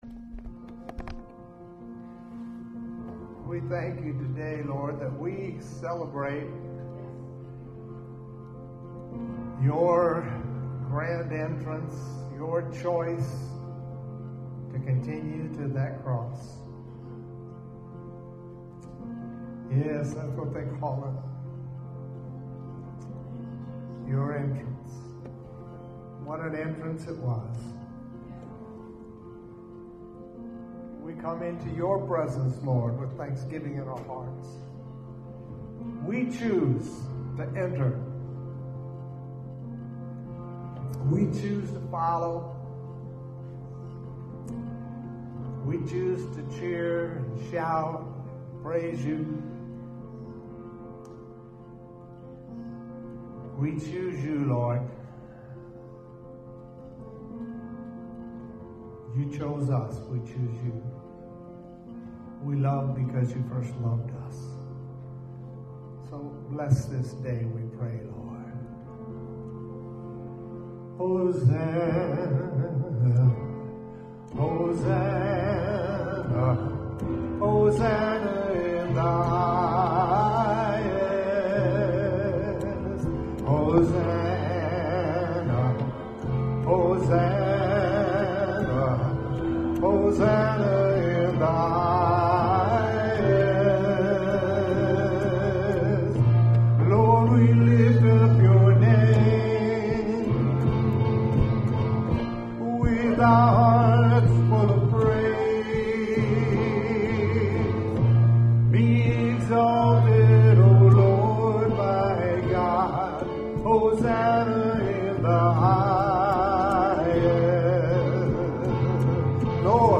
WORSHIP 0329.mp3